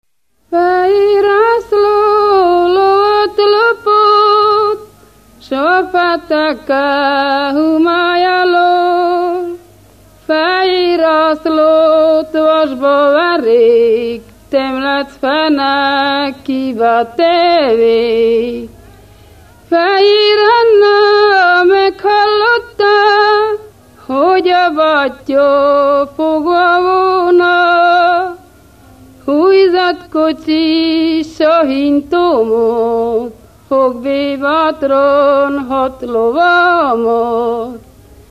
Moldva és Bukovina - Moldva - Lészped
ének
Műfaj: Ballada
Stílus: 1.2. Ereszkedő pásztordalok
Szótagszám: 8.8.8.8
Kadencia: 7 (5) b3 1